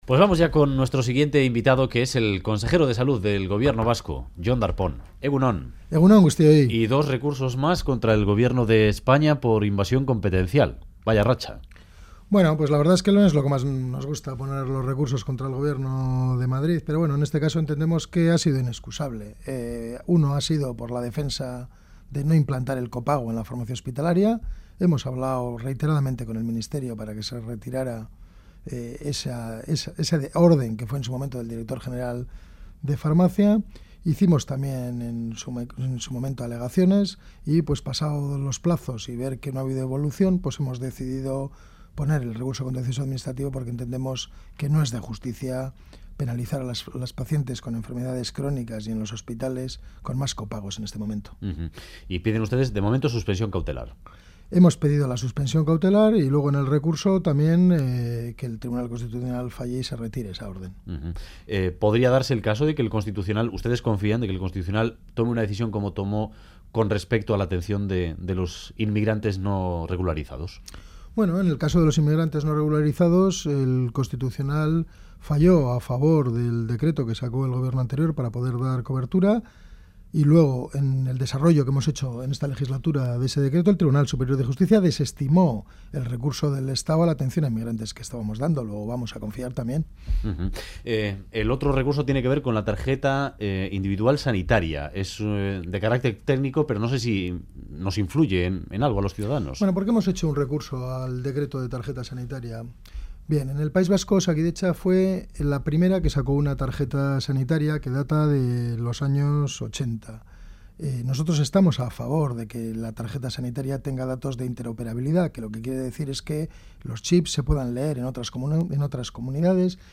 Radio Euskadi BOULEVARD Darpón: 'Recurrimos el copago hospitalario porque nos parece injusto' Última actualización: 10/03/2014 10:06 (UTC+1) En entrevista al Boulevard de Radio Euskadi, el consejero de Salud y Consumo, Jon Darpón, ha confirmado la presentación de dos nuevos recursos contra el copago hospitalario y la regulación de la Tarjeta Sanitaria. El primero, porque consideran injusto castigar a los pacientes crónicos en los hospitales con más copagos, y el segundo por invasión de competencias.